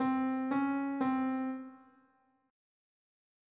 Minor 2nd (m2nd)
A Minor 2nd is the relationship between a C and a C# located a half step above.